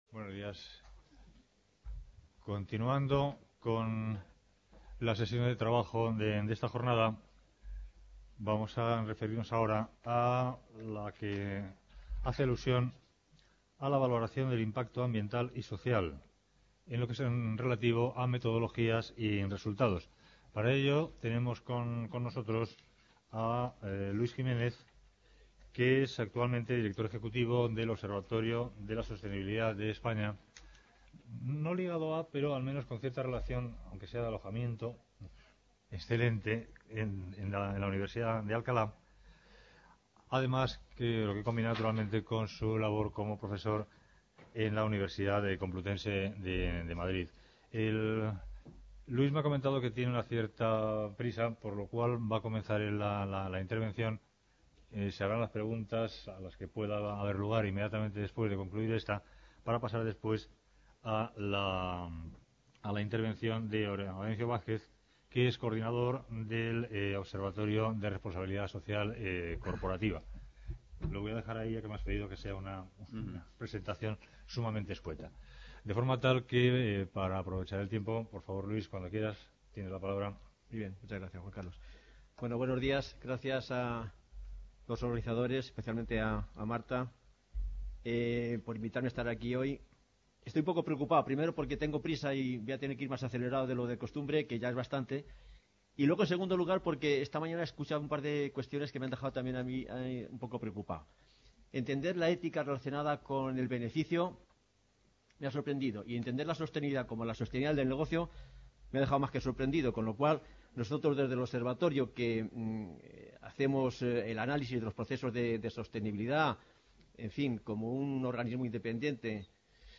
Reunion, debate, coloquio...